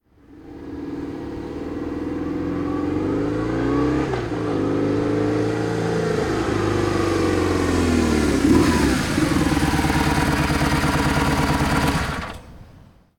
Moto marca BMW llega y para a velocidad normal
motocicleta
Sonidos: Transportes